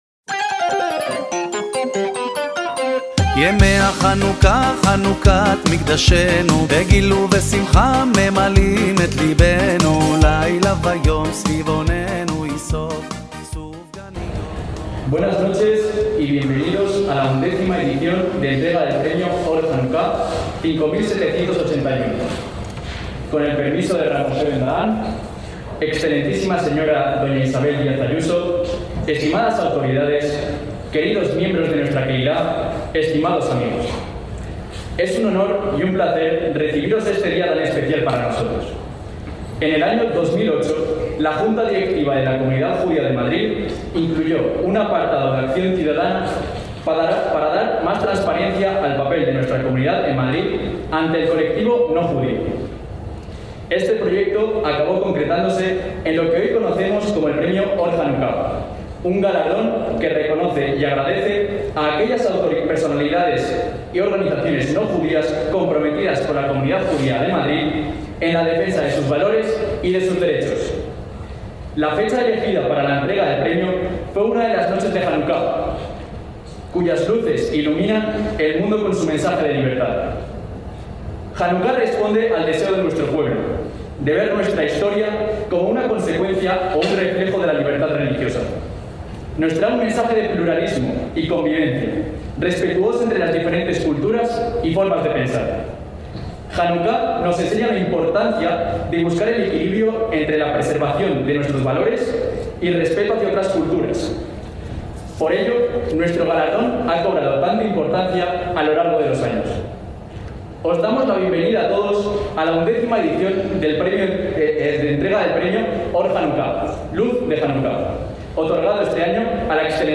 Premio Or Janucá 5781 (Sinagoga Beth Yaacov, Madrid, 10/12/2020)
ACTOS EN DIRECTO - La Comunidad Judía de Madrid celebraron el 10 de diciembre de 2020 el acto de entrega del Premio Or Janucá 5781, que en esta XI edición fue otorgado a la Excma. Sra. Dª Isabel Díaz Ayuso, Presidenta de la Comunidad Autónoma de Madrid, por la pionera modificación en el currículo académico de historia en los institutos de la Comunidad de Madrid, incluyendo un mayor contenido sobre la cultura y el legado judío en España, constituyendo un hito y ejemplo para otras Comunidades Autónomas, así como su reconocimiento y empuje de la especificidad judía del colegio concertado Ibn Gabirol y de la libertad de enseñanza en nuestra Autonomía.